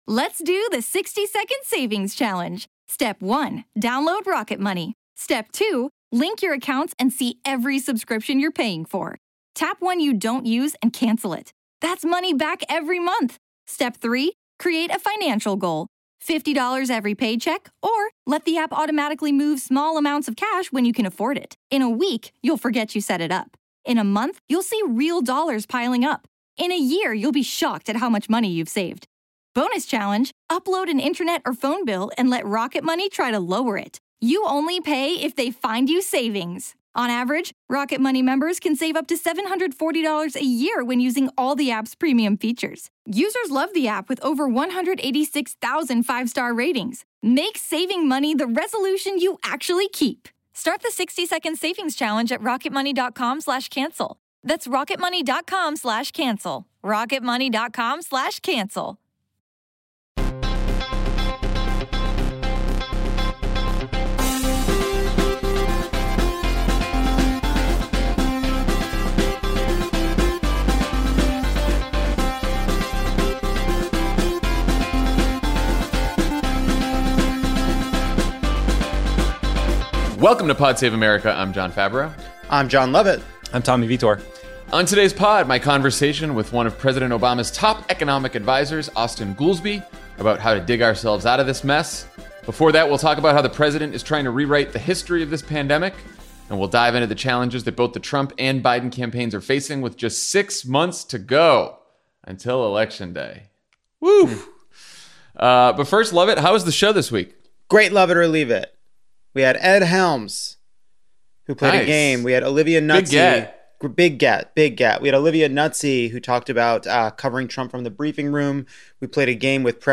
Then Austan Goolsbee, one of Obama’s top economic advisors, talks to Jon F. about how to dig ourselves out of this crisis.